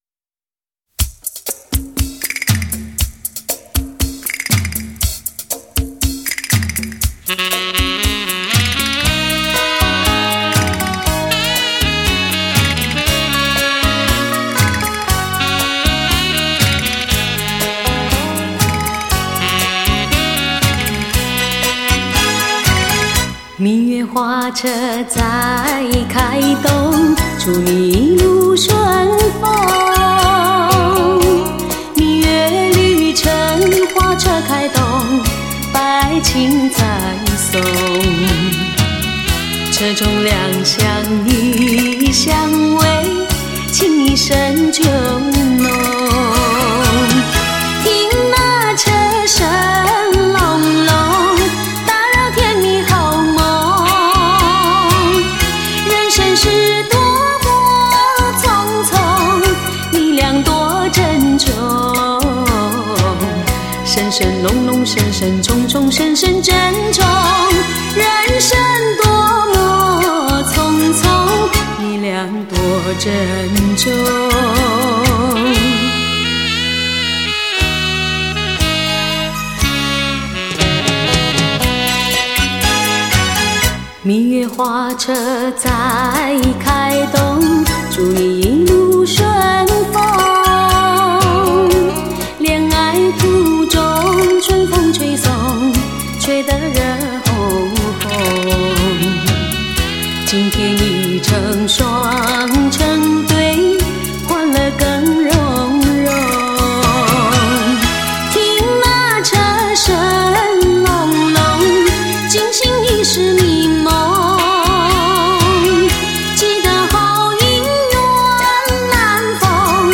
吉他主音
贝斯
鼓
键盘
木吉他
小提琴
古筝
二胡
笛子
扬琴
琵琶
和音